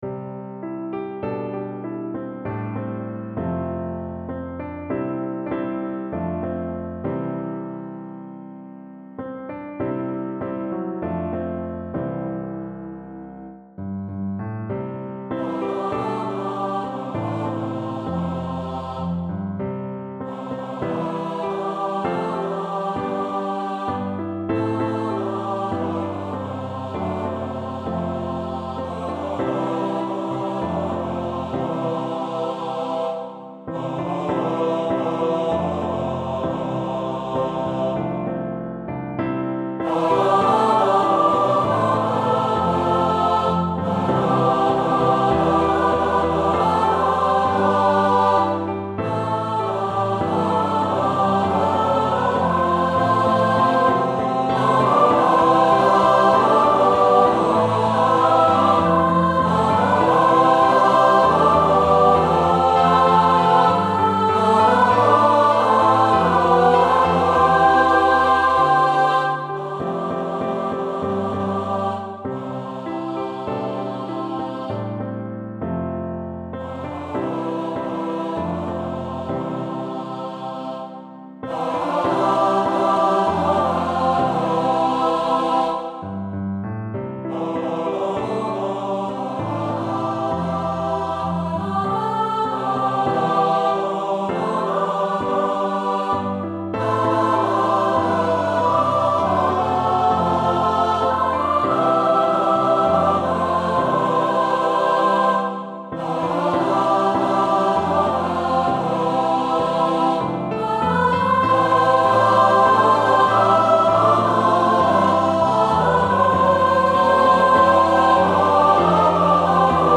for mixed voices with piano